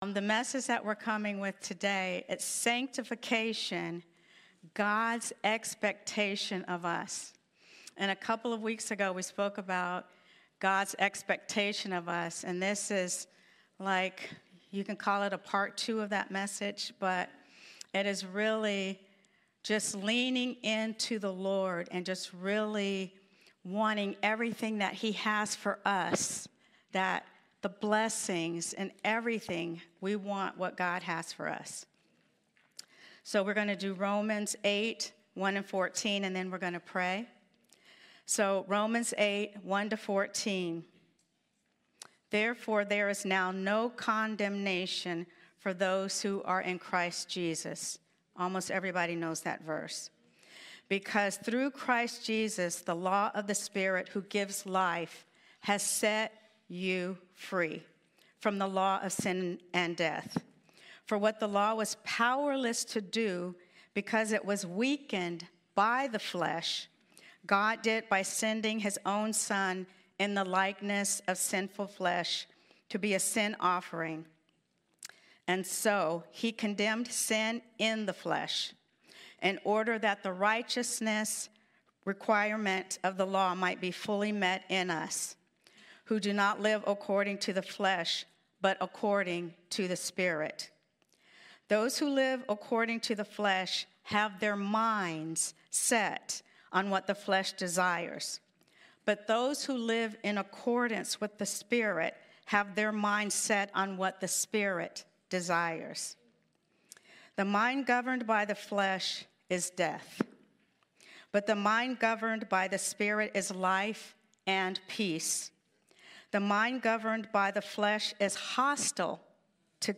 This podcast contains the weekly teaching from Abundant Life Christian Fellowship in Mountain View California.